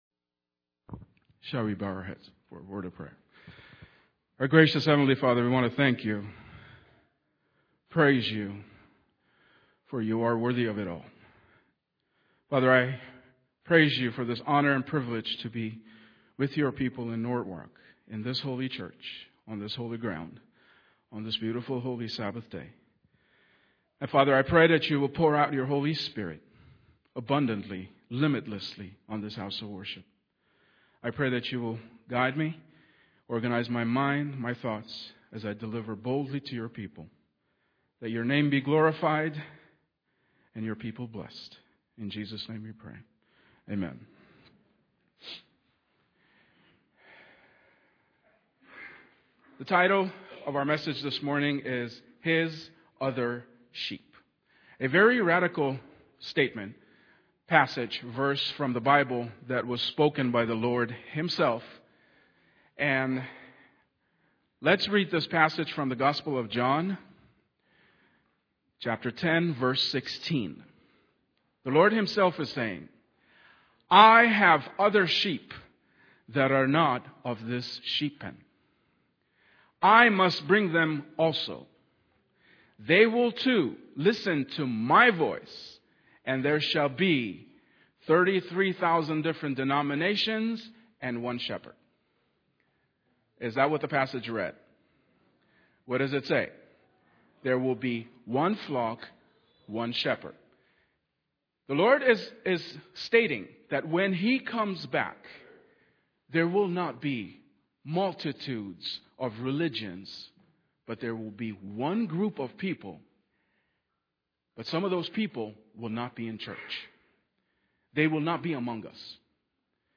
2015 Sermons